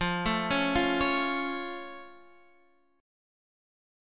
F+ Chord
Listen to F+ strummed